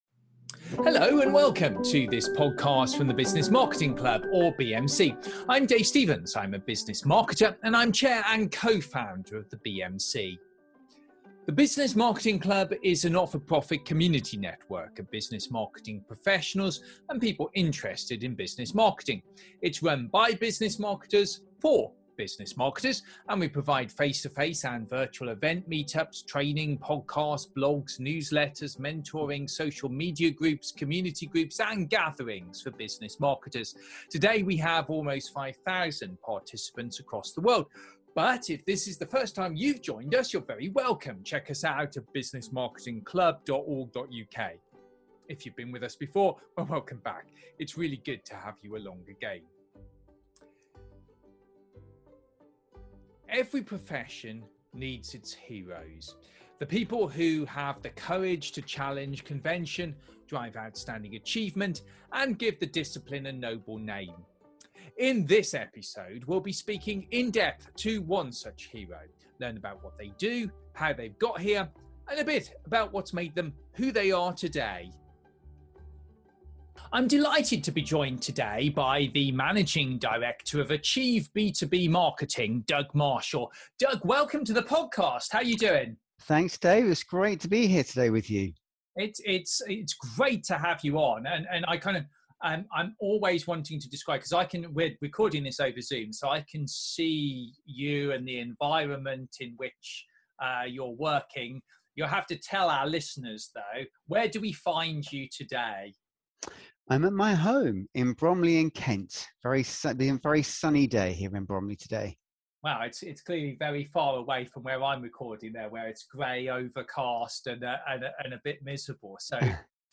Business Marketing Heroes – An Interview